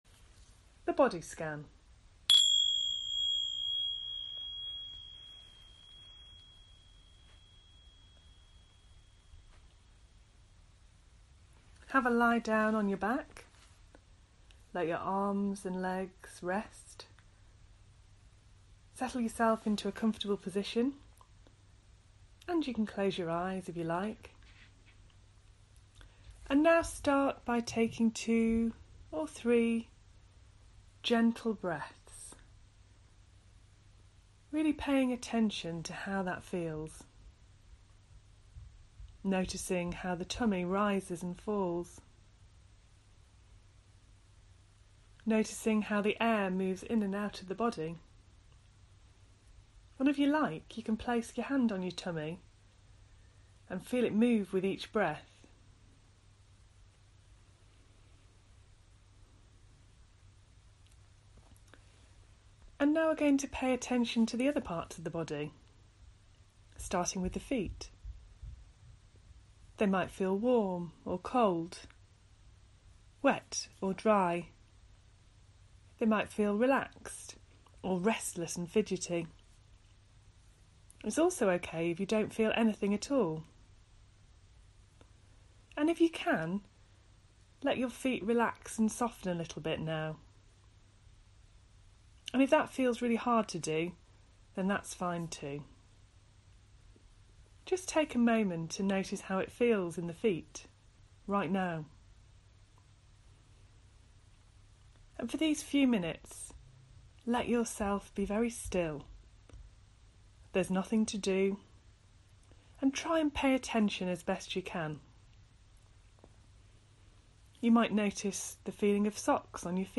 Follow this audio body scan to practice your mindfulness